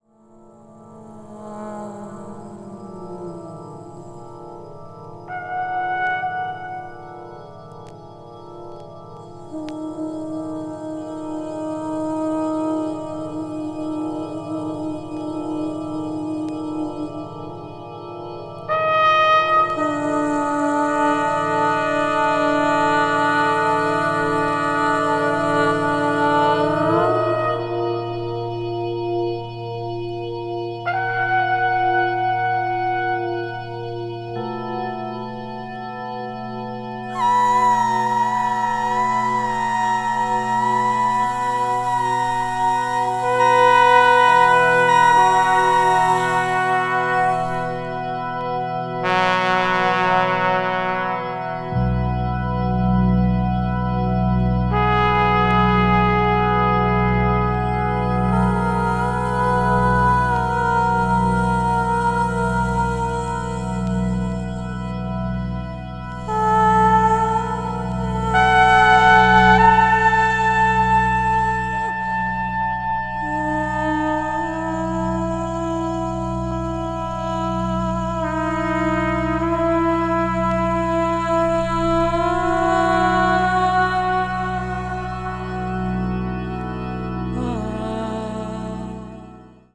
ambient jazz   contemporary jazz   deep jazz